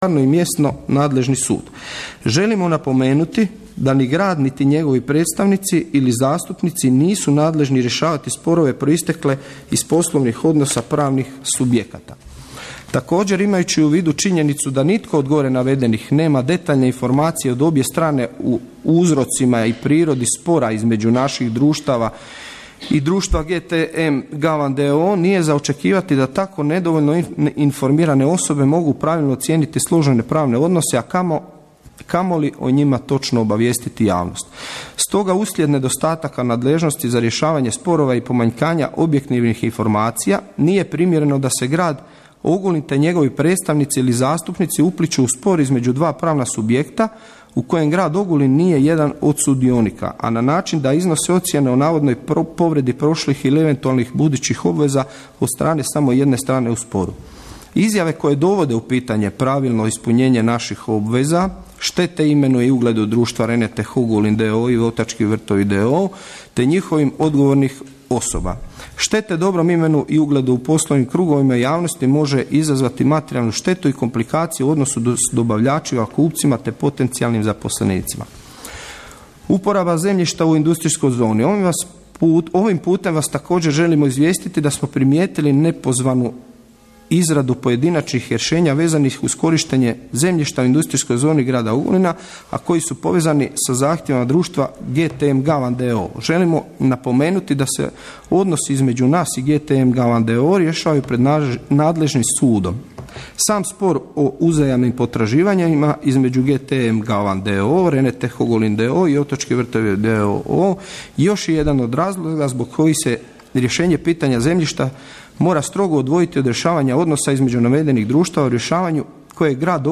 Odluke 7. sjednice Gradskog vijeća Grada Ogulina održane 16. ožujka 2018. godine u 11:00 sati u Velikoj dvorani Gradske knjižnice i čitaonice Ogulin, Bernardina Frankopana 7.